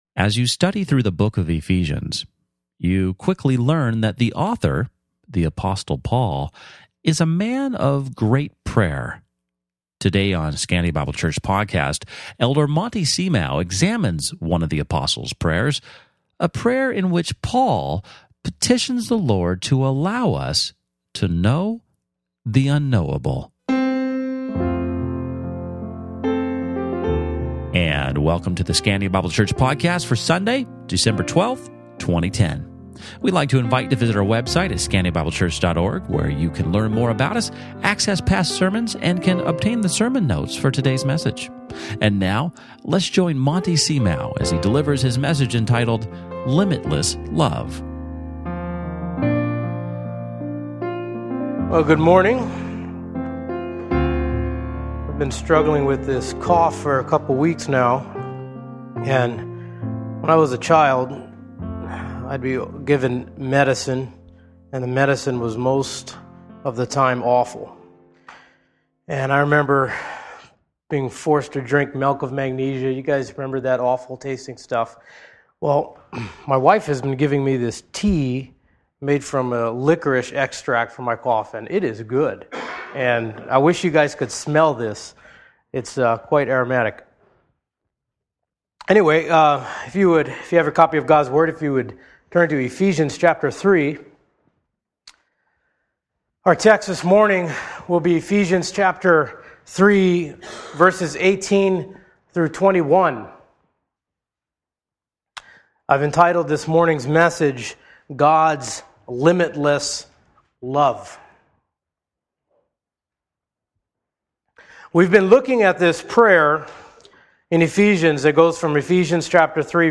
Sermon Notes